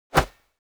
dalanqiu.wav